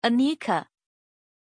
Aussprache von Anikka
pronunciation-anikka-zh.mp3